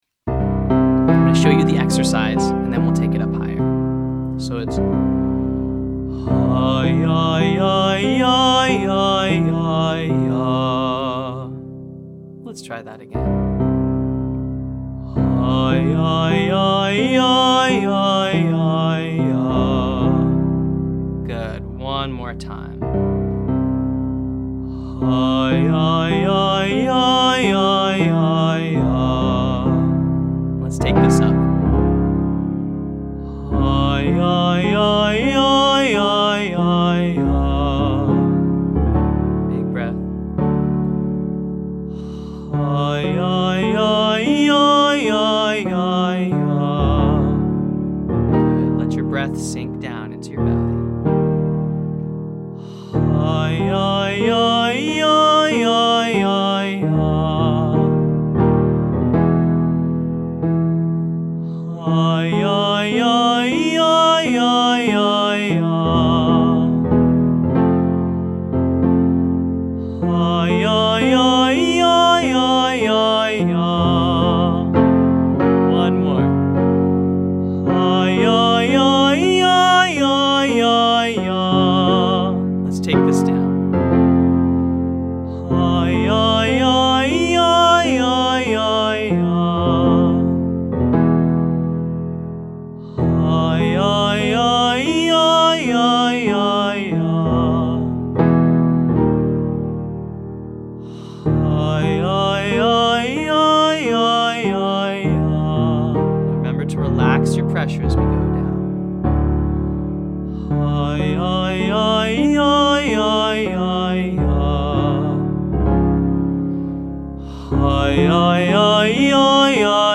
• Huyahyahyah (1, 3, 5, 8, 5, 3, 1)